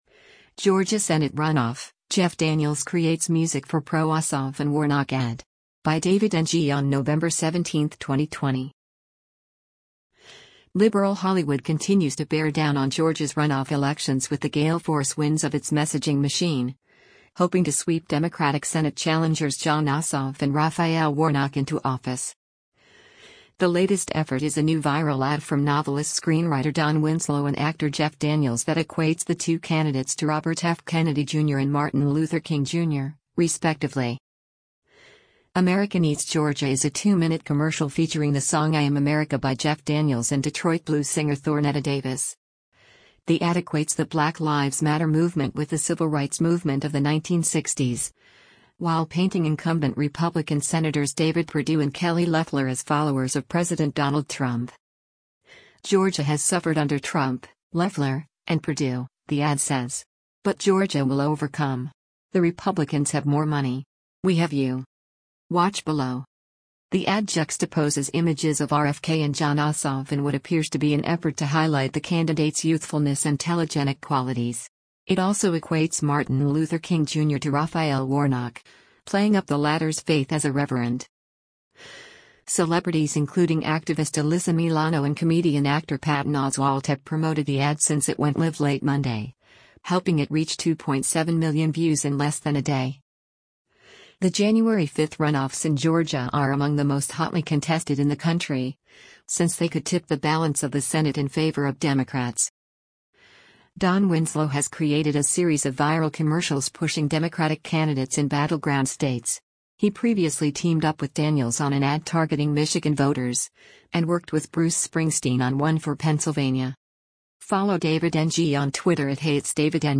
two-minute commercial
blues singer